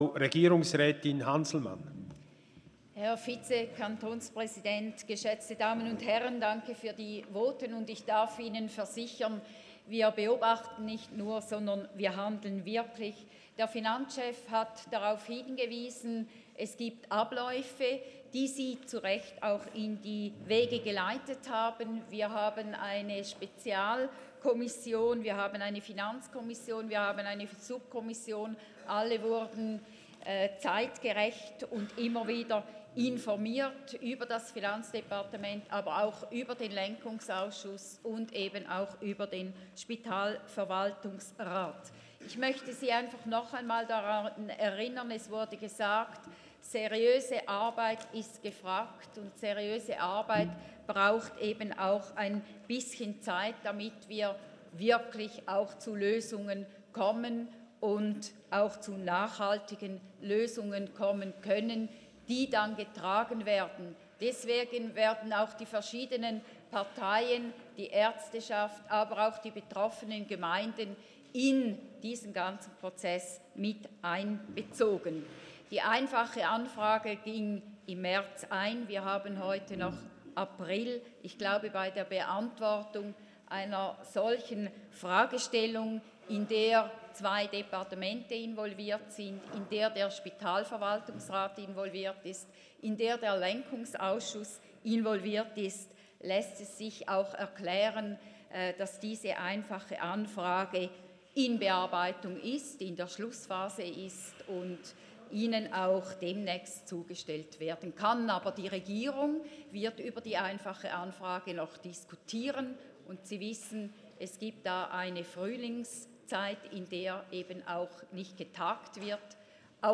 Regierungsrätin:
Session des Kantonsrates vom 23. und 24. April 2019